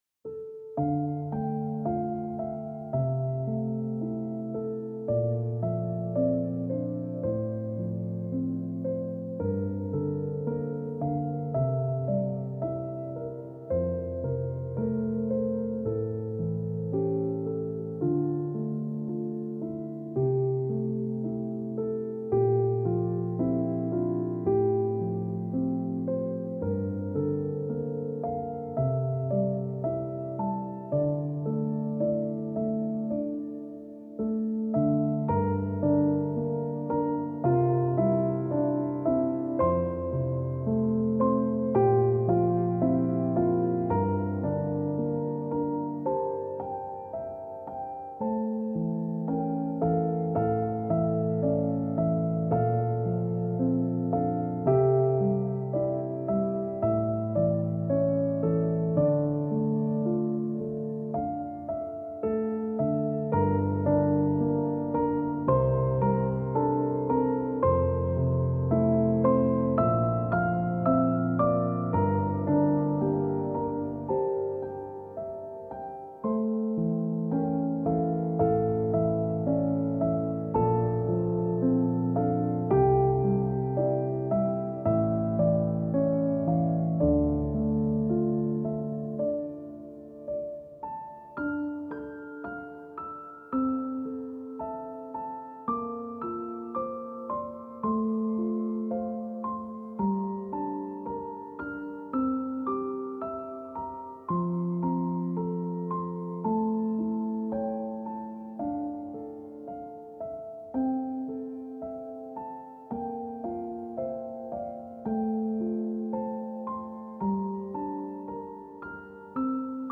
آرامش بخش , پیانو , عاشقانه , عصر جدید , موسیقی بی کلام